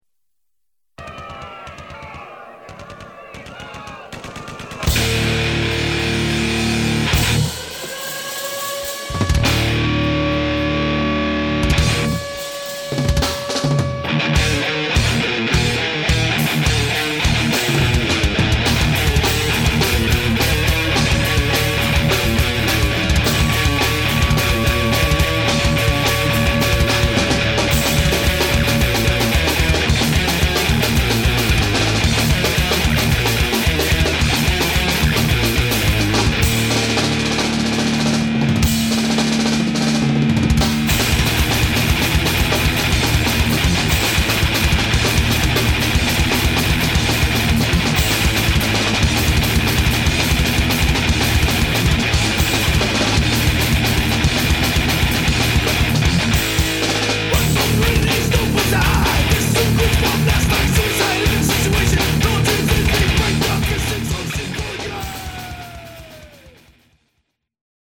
Guitars/Vocals
Bass
Drums
Thrash Metal